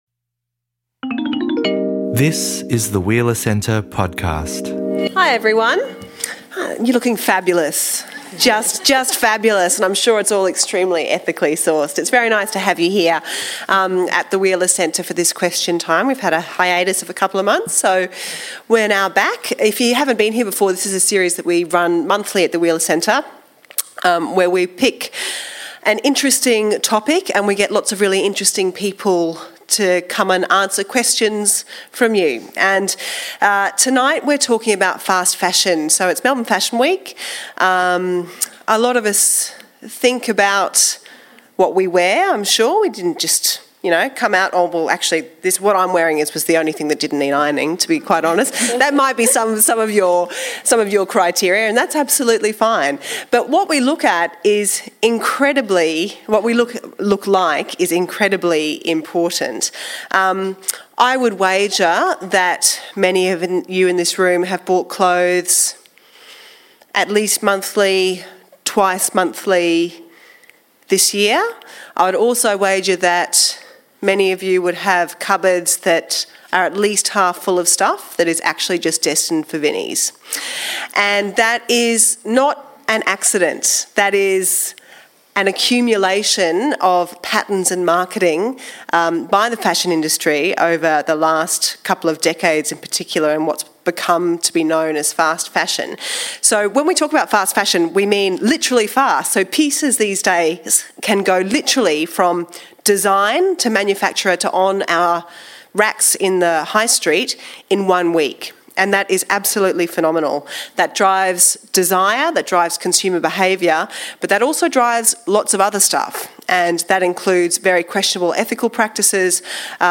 Is fast fashion going out of fashion? In this Question Time discussion, our panel field questions on the issue from all angles. Does the fast fashion business model preclude ethical or sustainable practices?